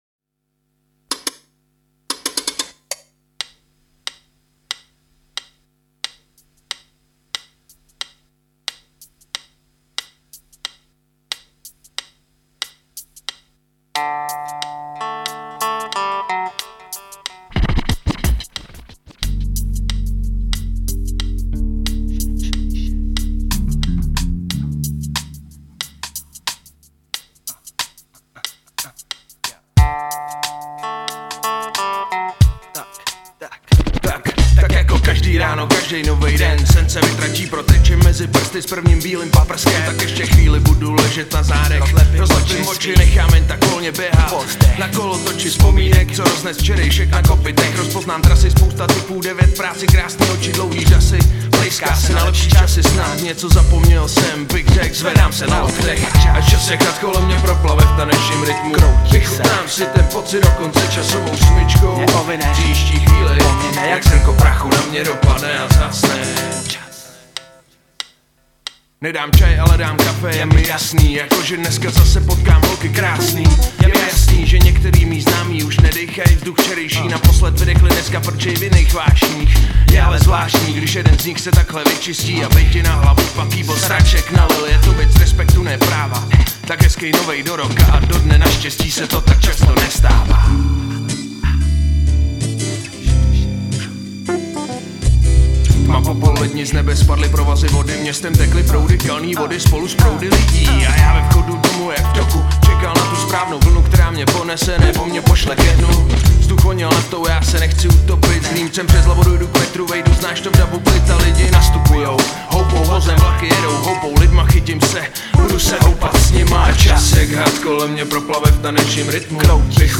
2 Styl: Hip-Hop Rok